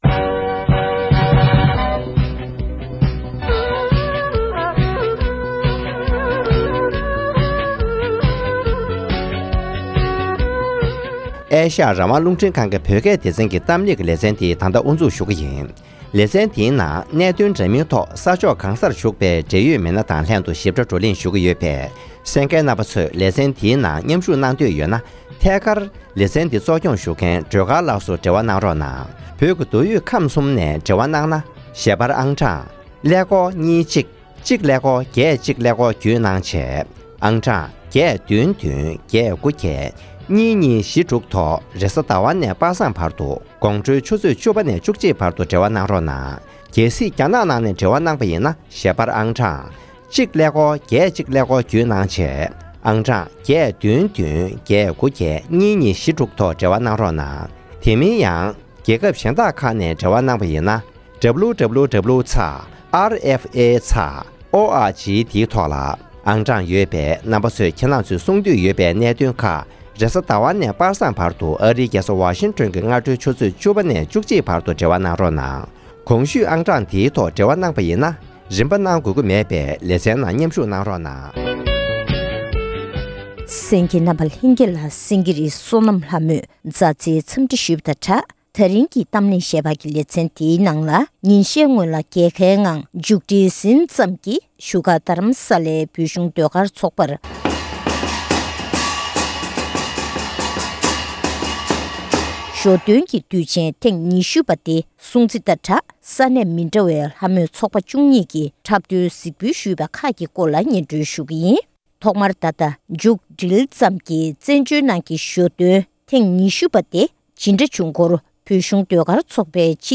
བཞུགས་སྒར་དྷ་རམ་ས་ལའི་བོད་ཀྱི་ཟློས་གར་དུ་མཇུག་བསྒྲིལ་ཟིན་ཙམ་ཡིན་པའི་ཞོ་སྟོན་རྒྱས་པ་ཐེངས་༢༠སྐོར་འབྲེལ་ཡོད་མི་སྣར་གནས་འདྲི་དང་བཙན་བྱོལ་བོད་མིའི་ལྷ་མོའི་ཚོགས་པ་༡༢ཡིས་ལྷ་མོའི་འཁྲབ་གཞུང་གཟིགས་འབུལ་ཞུས་པ་ཁག་ཕྱོགས་བསྡུས་ཞུས་པ་ཞིག